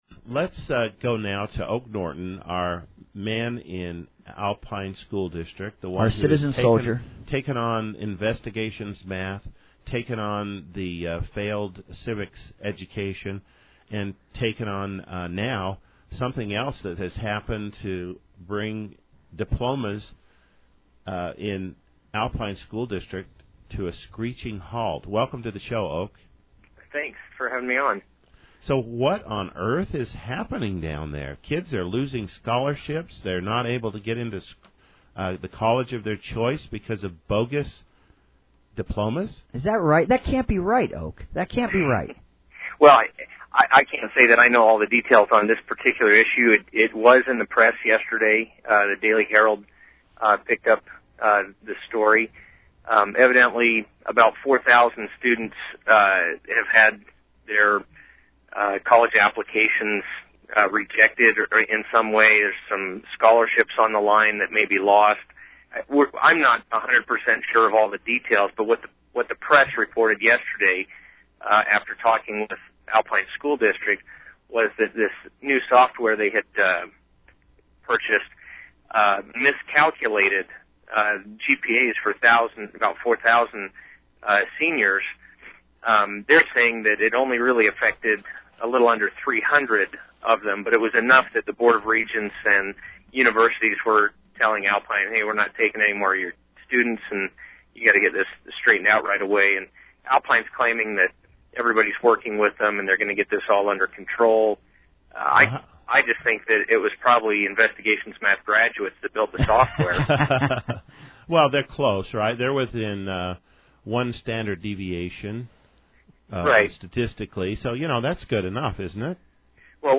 This morning I was on Red Meat Radio with Senator Howard Stephenson and Representative Greg Hughes talking about the news of the week.